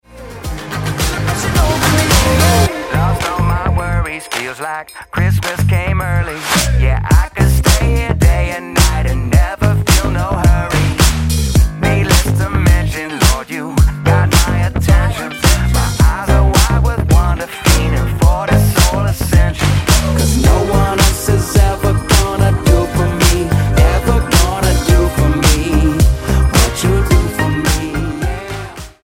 STYLE: Pop
vivacious and rocking